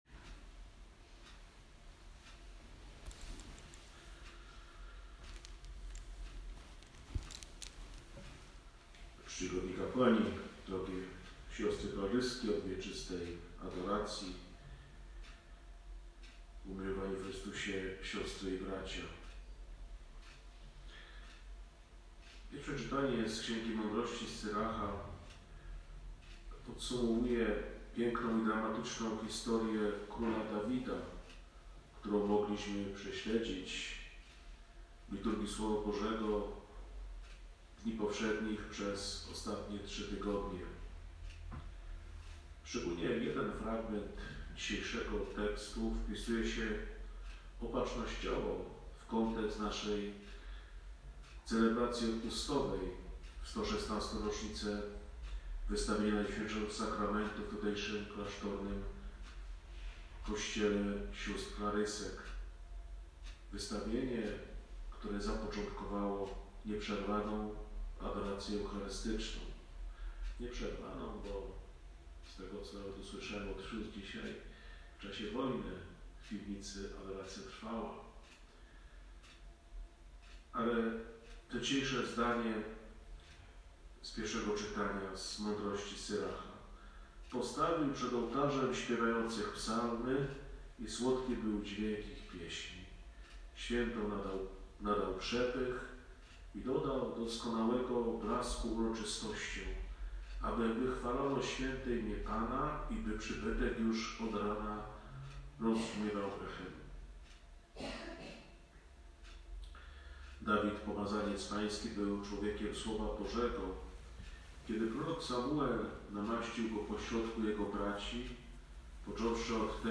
Odpust w naszym Sanktuarium – Kęty – Siostry Klaryski od Wieczystej Adoracji
6 lutego celebrowałyśmy uroczystość odpustową w naszym Sanktuarium i 116 rocznicę wystawienia Najświętszego Sakramentu na nasz Tron Eucharystyczny. Wspólnej modlitwie dziękczynnej przewodniczył Ks. Bp Robert Chrząszcz, pomocniczy biskup krakowski, pochodzący z niedalekiej Kalwarii Zebrzydowskiej. 6 lutego 2021 r. w Sanktuarium św.